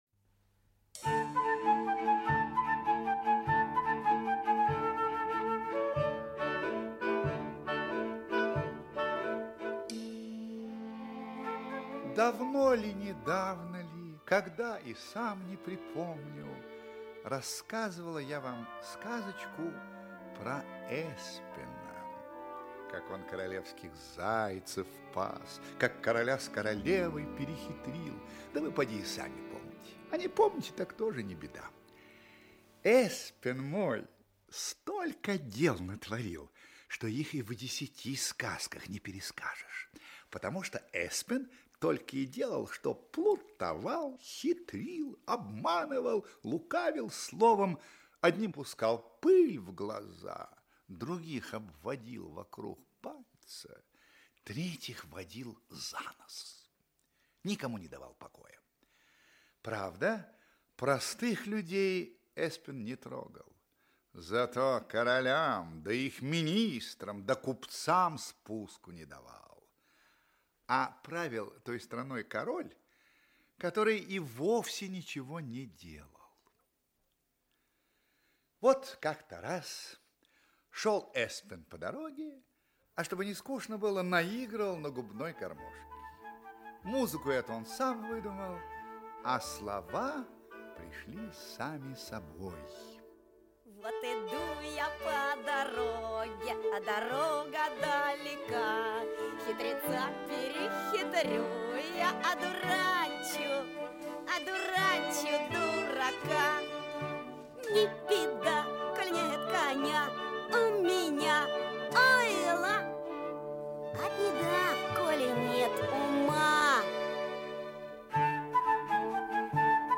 Аудиокнига Плутишка Эспен | Библиотека аудиокниг
Aудиокнига Плутишка Эспен Автор Петер Асбьёрнсен Читает аудиокнигу Георгий Вицин.